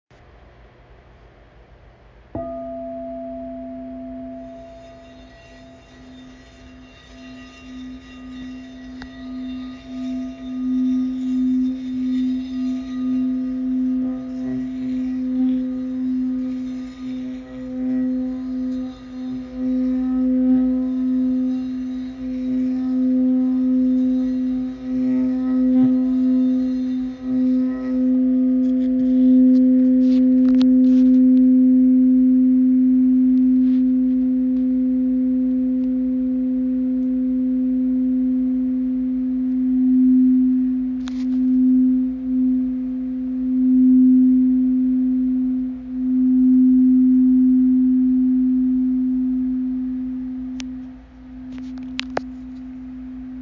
Crystal Bowl – 7 Bowl Chakra Set
8″ = B, 8″ = A, 8″ = G, 9′ = F, 10′ = E, 11″ = D, 12″ = C
This set is based on A = 432.
The frosted white bowls produce full, sonorous tones that ring on-and-on with incredible harmony. There are beautiful interplays of resonances and after-tones.
C-crystal-bowl.mp3